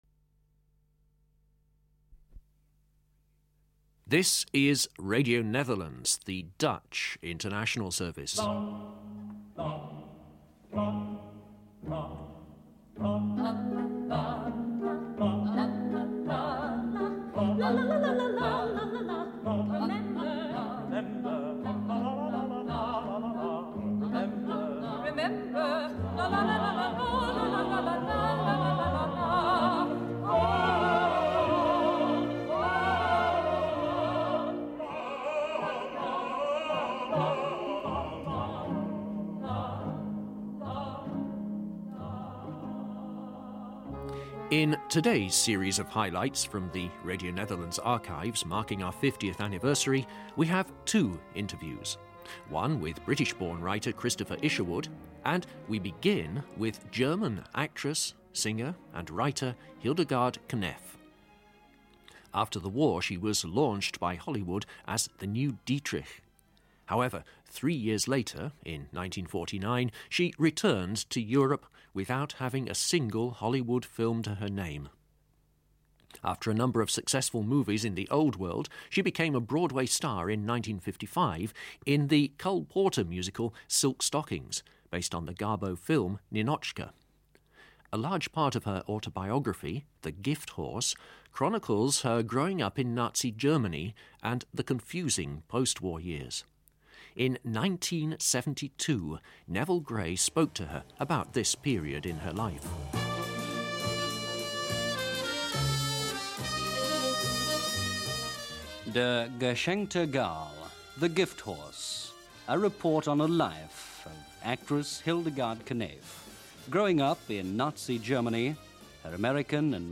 Mirror-Images-Interviews-with-Hildegard-Kneff-and-Christopher-Isherwood.mp3